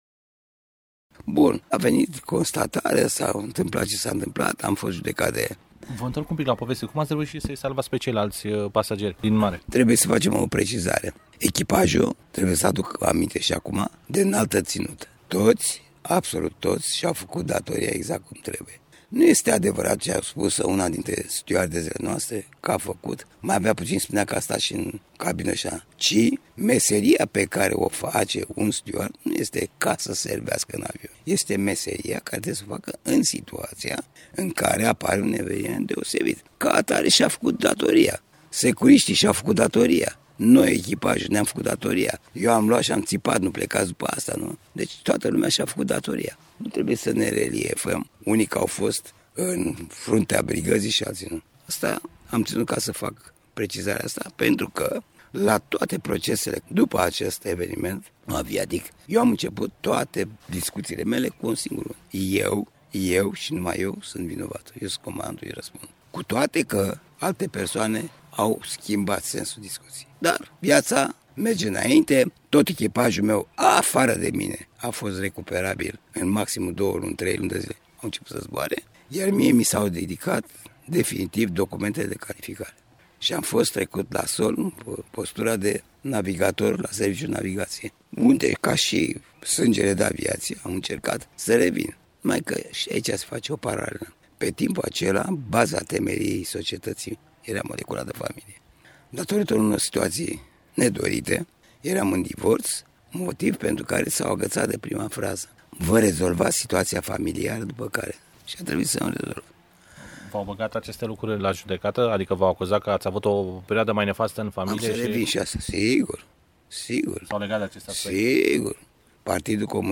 V-am pregătit un interviu cu adevărat inedit, pe care vă invit să îl savurați pe îndelete pentru că veți afla informații și detalii extrem de interesante și unice.